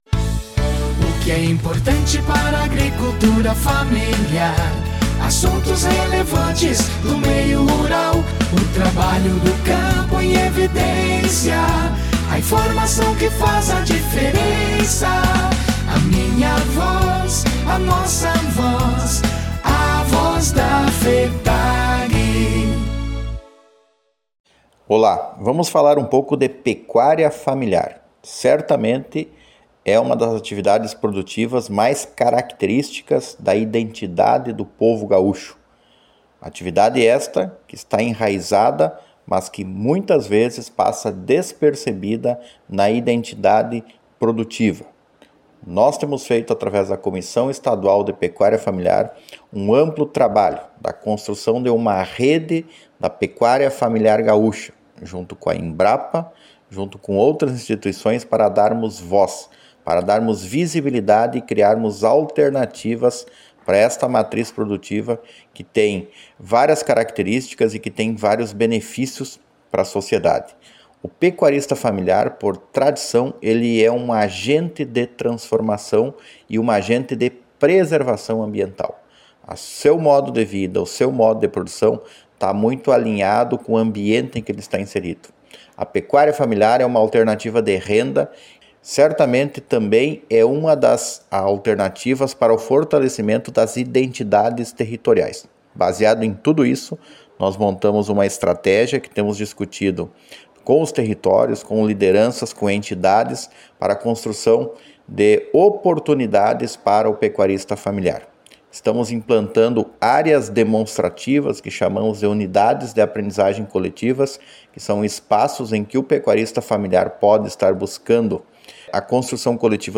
Programa de Rádio A Voz da FETAG-RS
Nova-Voz-da-Fetag-Instrumental-com-encerramento26.9.mp3